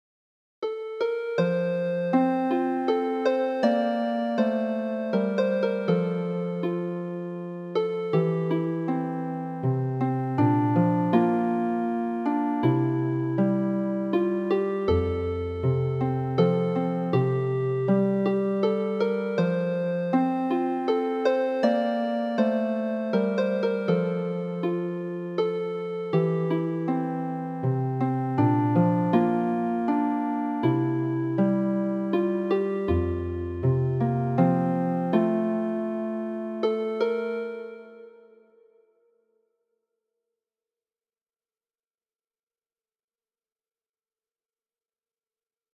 contemporary waltzes
They fall really well on the harp.